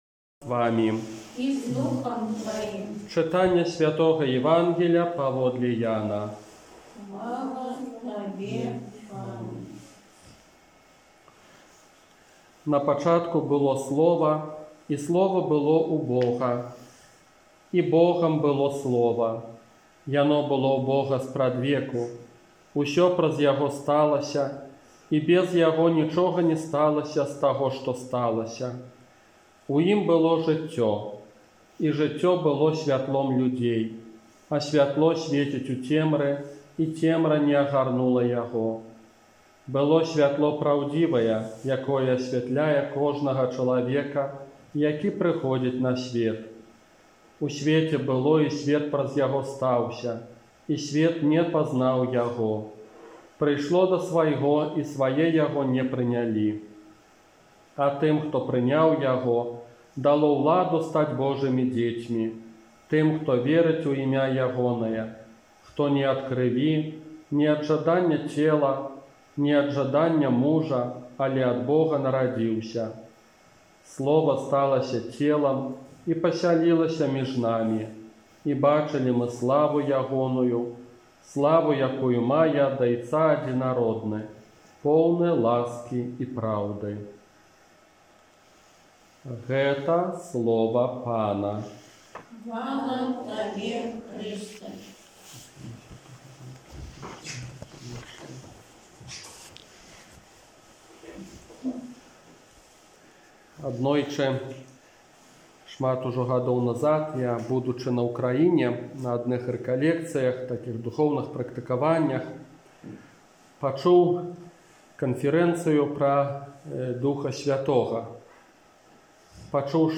Казанне на другую нядзелю пасля Нараджэння Пана